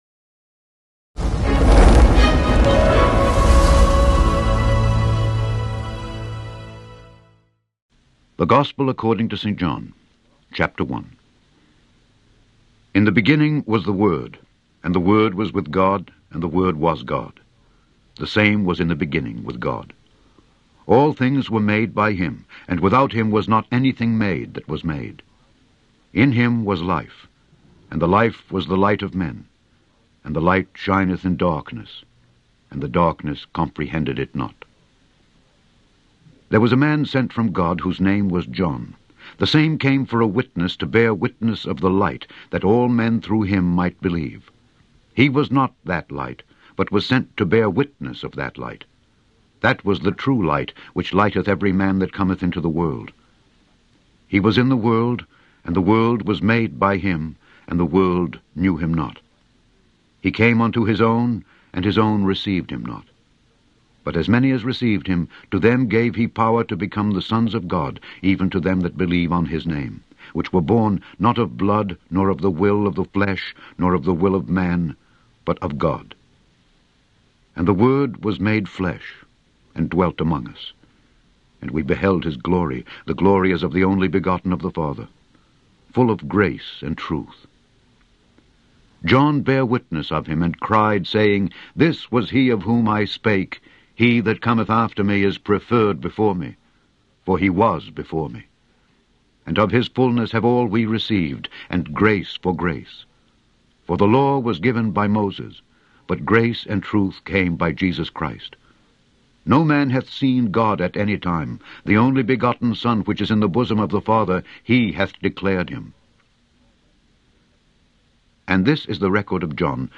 Daily Bible Reading: John 1-2
Click on the podcast to hear Alexander Scourby read John 1-2.